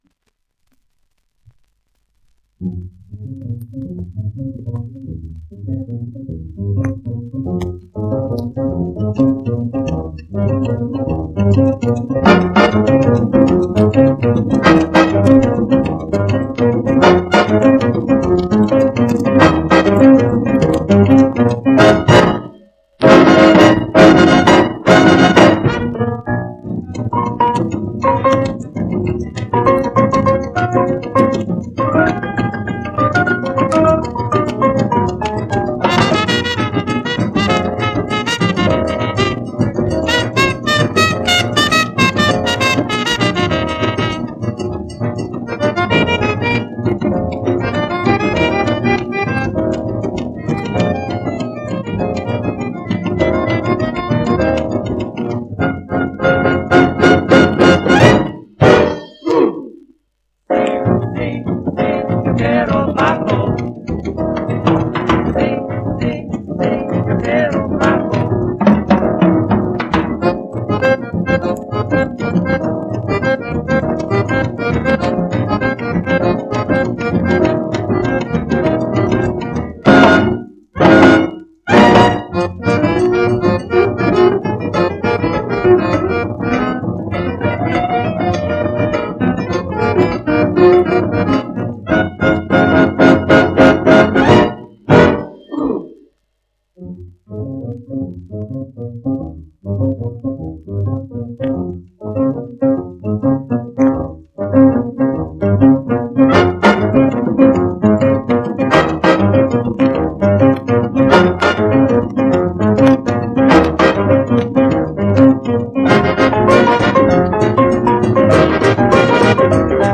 1 disco : 78 rpm ; 25 cm Intérprete
con su acordeón y su banda